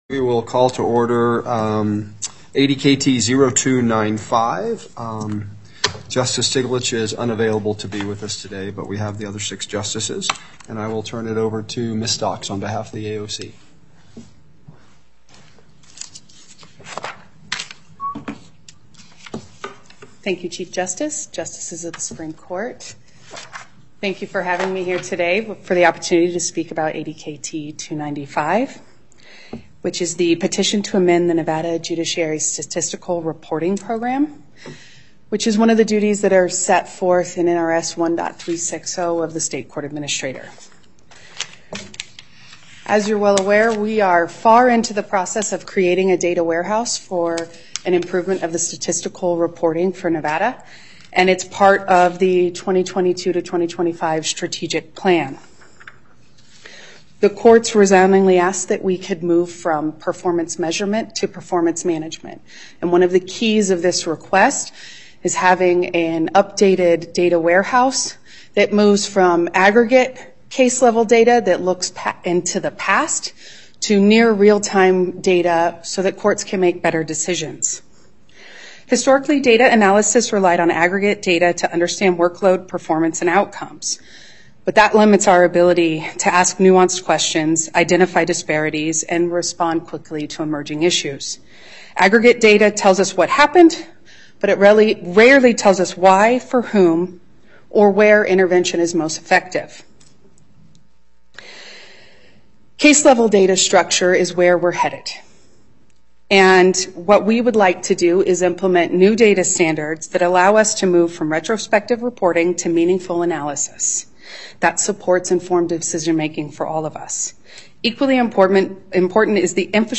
Before the En Banc Court, Chief Justice Herndon presiding